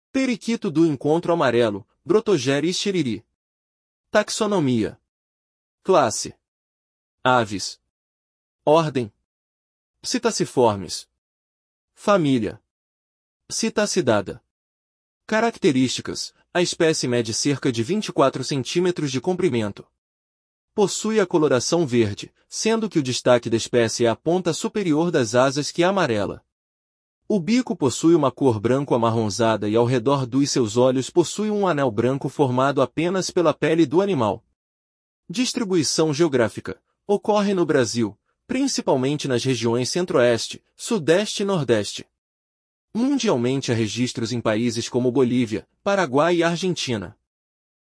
Periquito-do-encontro-amarelo (Brotogeris chiriri)
Classe Aves